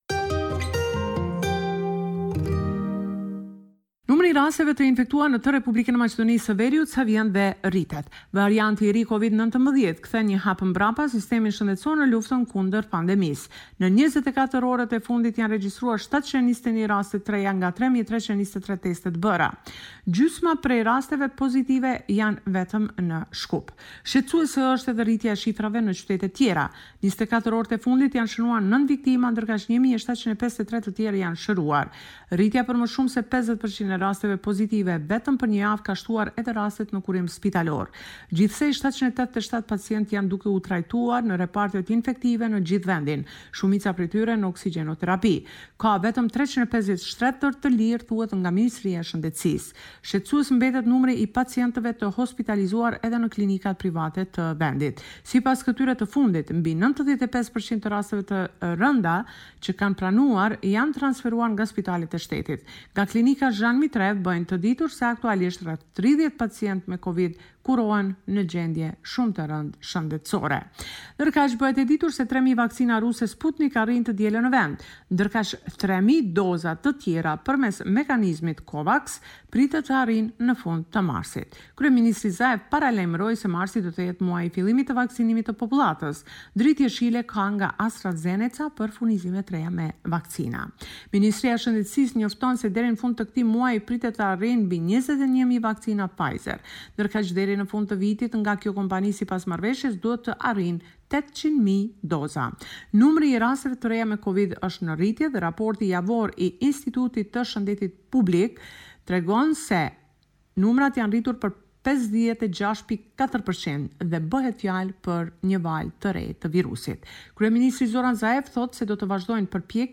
This is a report summarising the latest developments in news and current affairs in North Macedonia.